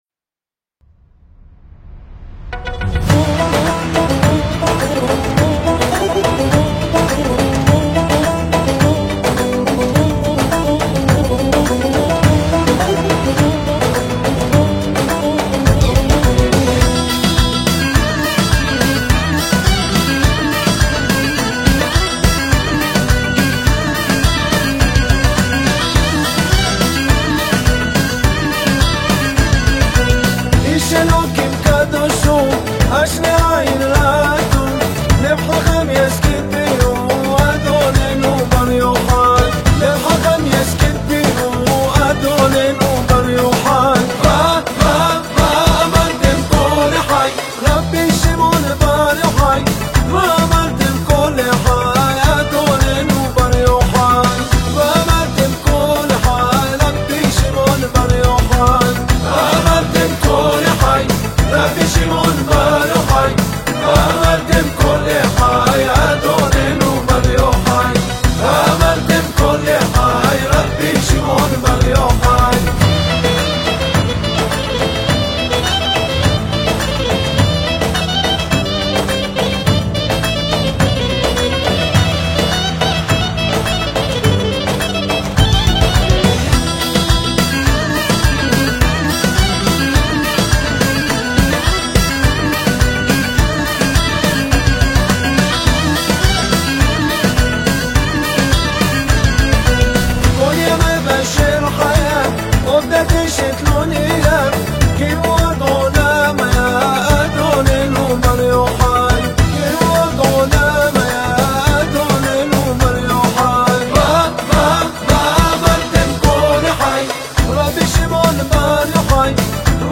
לחן: כורדי עממי.